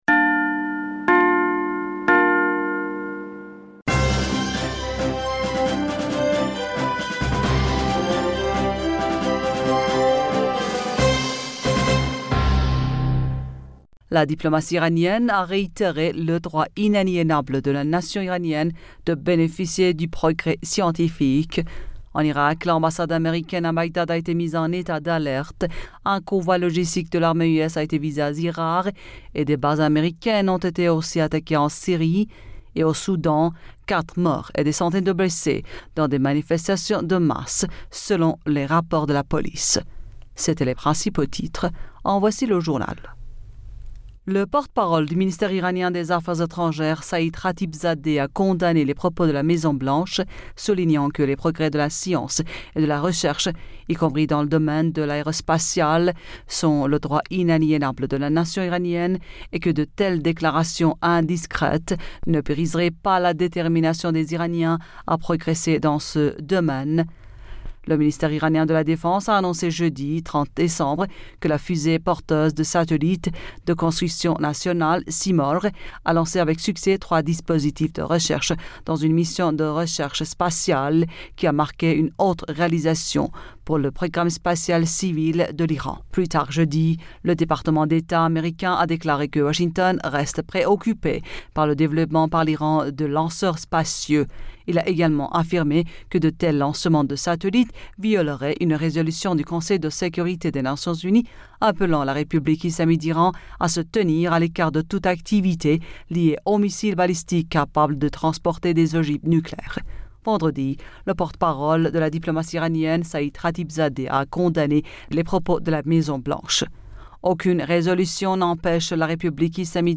Bulletin d'information Du 01 Janvier 2022